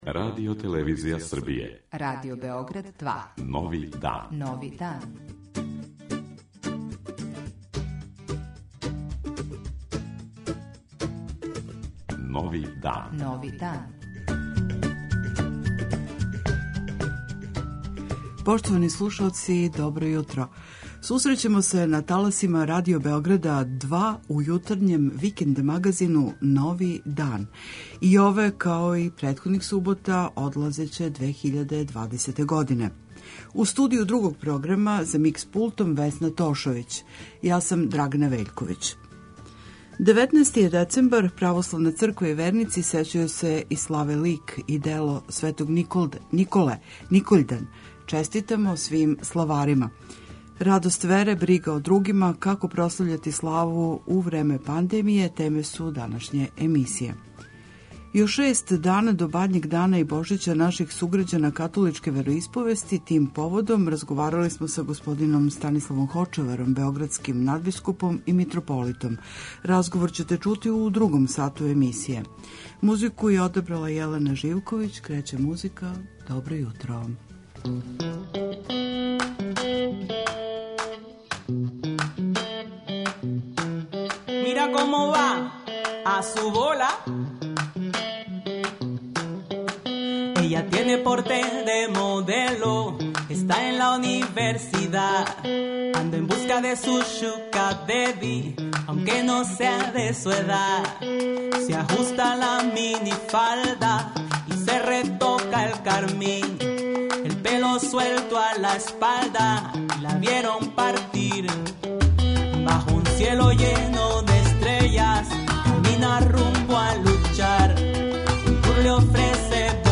Благоухани мирис љубави - Дух Свети као небески огањ уноси топлину љубави у срца људска Како ће наши суграђани римокатоличке вероисповести прославити Божићне празнике ове године. За слушаоце Новог дана Радио Београда 2 говори Његова преузвишеност господин Станислав Хочевар, београдски надбискуп и митрополит.